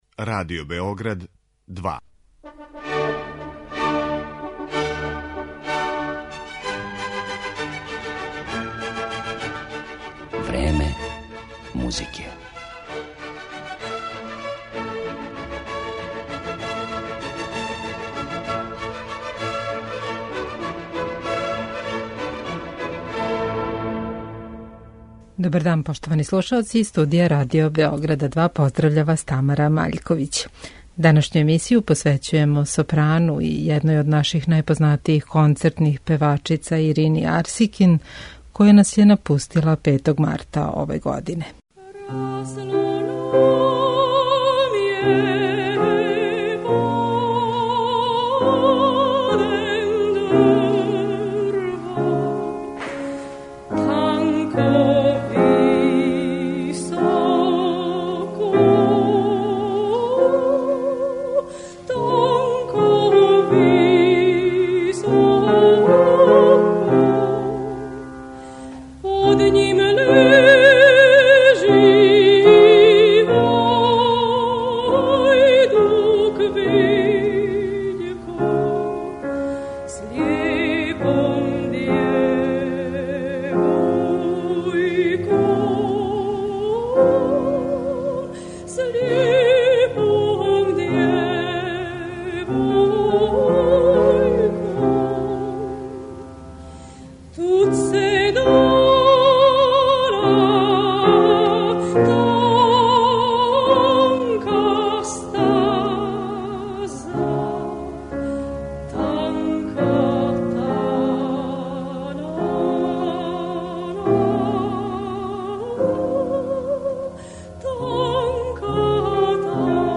Почетком марта ове године преминула је Ирина Арсикин, наша најистакнутија концертна певачица.
Овој изузетној уметници и њеним интерпретацијама посвећујемо емисију Време музике.